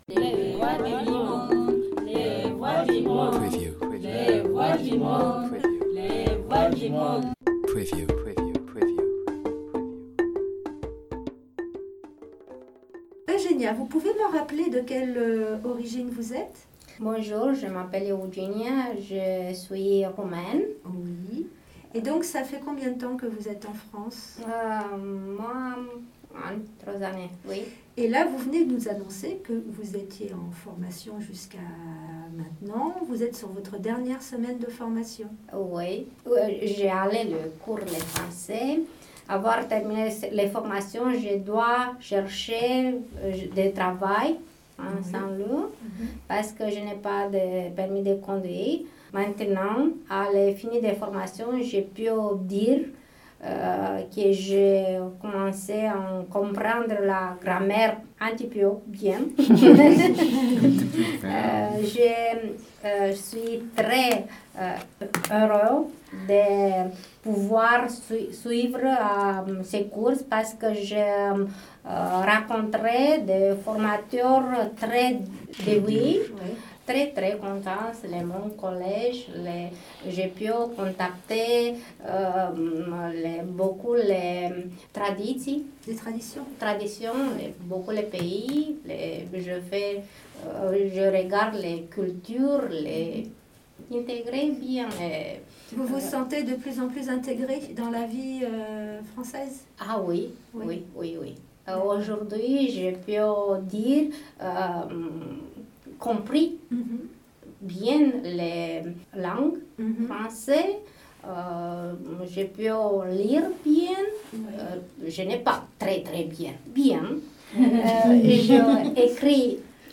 Les stagiaires du dispositif LEC « Lire, écrire, compter », en formation au centre social Mersier, poussent les portes du studio MDR afin d’améliorer leur expression orale et l’apprentissage de la langue française